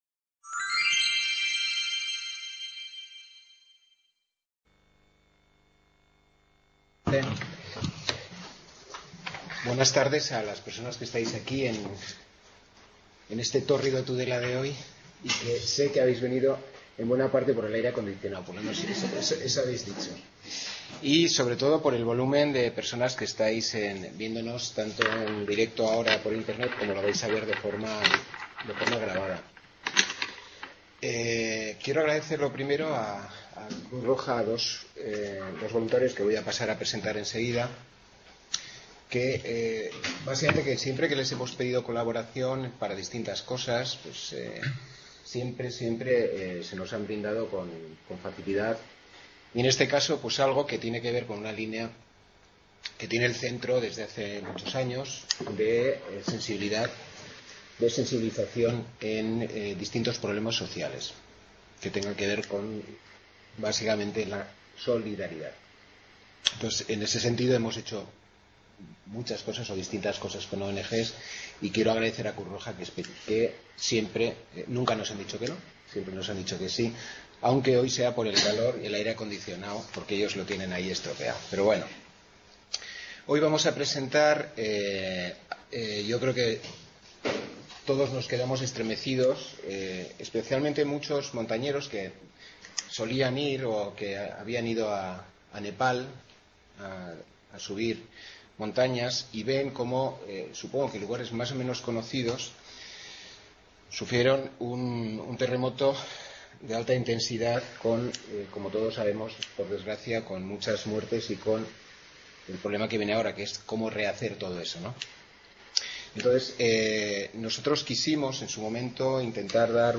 La UNED de Tudela acoge una actividad benéfica en la que participarán dos delegados de Cruz Roja en Navarra que contarán, de primera mano, la situación que han vivido y su trabajo sobre el terreno, asistiendo en los esfuerzos de búsqueda y rescate de personas y administrando primeros auxilios a los heridos.